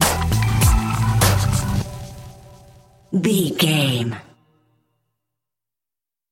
Aeolian/Minor
synthesiser
drum machine
hip hop
Funk
neo soul
acid jazz
energetic
cheerful
bouncy
funky